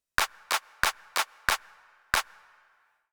Here you will find rhythms with various types of notes in the bar.
Four eighth notes, and two quarter notes.